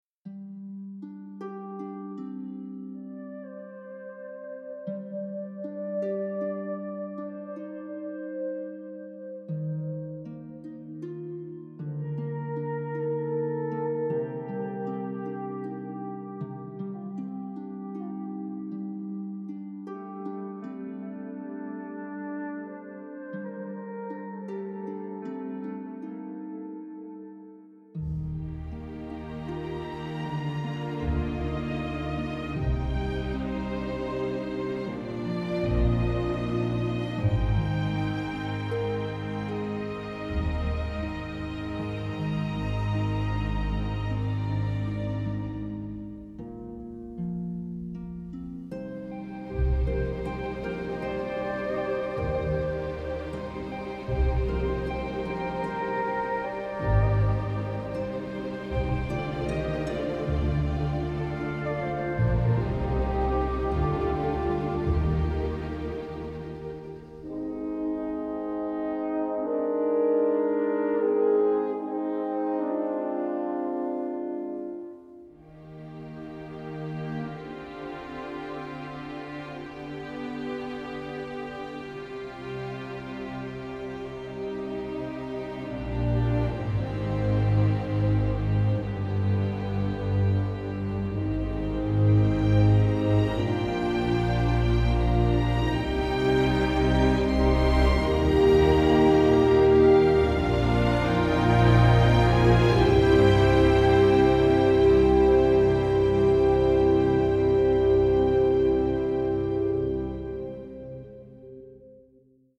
a delicate and quirky score